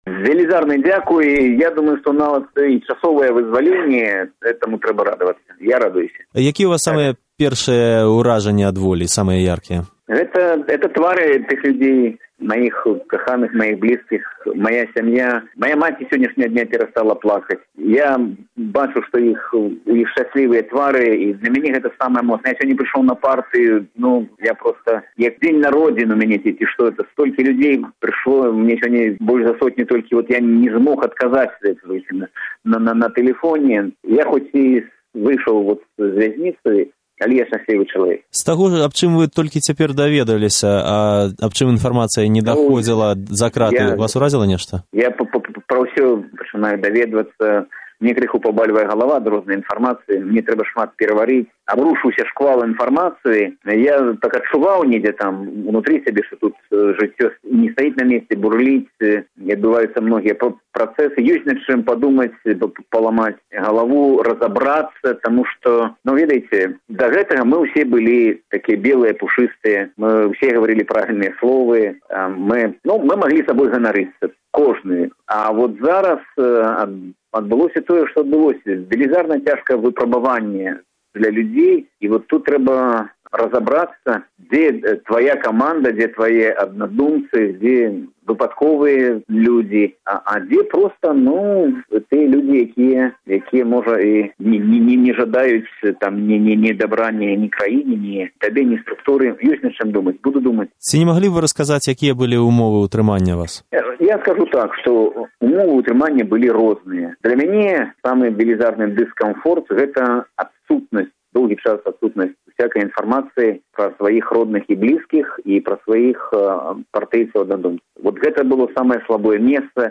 У чацьвер увечары лідэр Аб'яднанай грамадзянскай партыі Анатоль Лябедзька быў госьцем "Начной Свабоды". Увечары 6 красавіка ён быў вызвалены са сьледчага ізалятару КДБ.
Размова з Анатолем Лябедзькам 7 красавіка 2011 году.